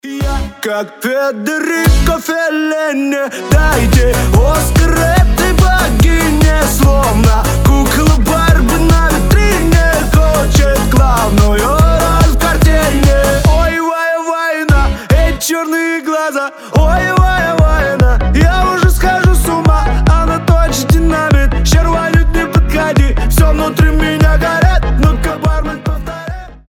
• Качество: 320, Stereo
позитивные
веселые